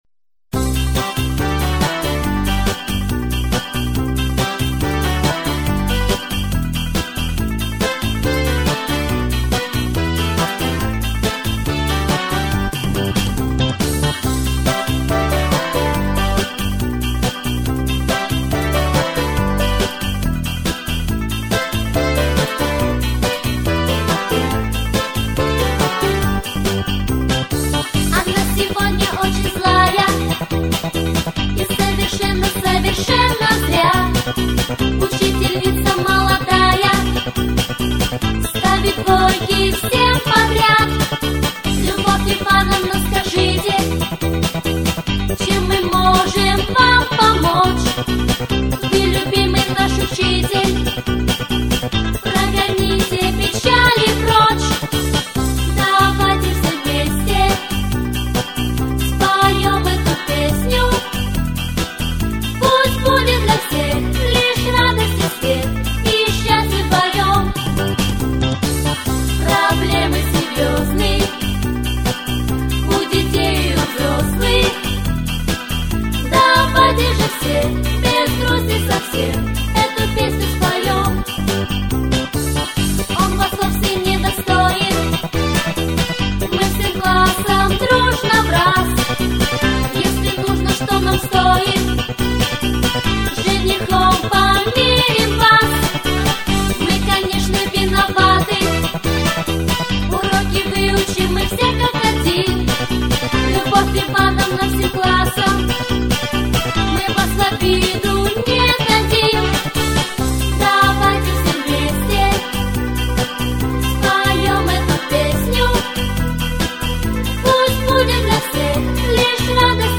🎶 Детские песни / Все Школьные песни / День учителя